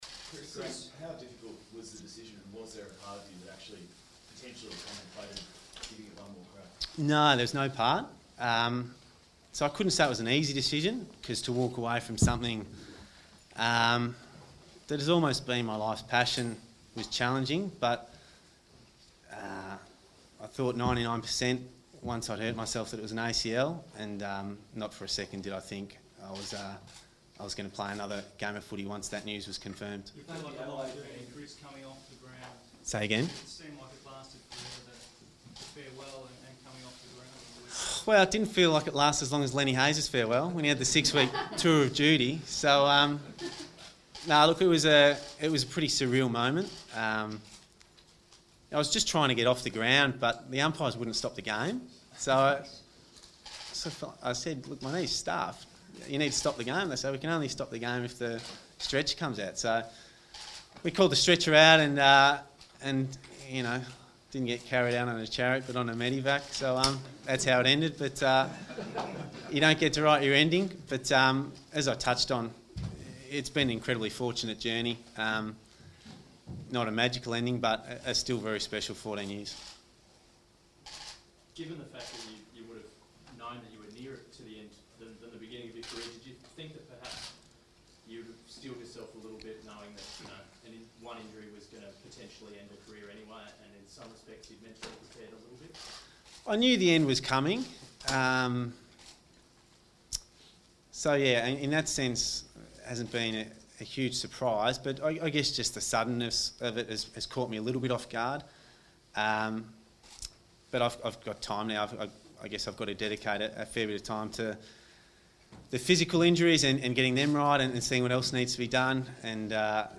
Chris Judd press conference - June 9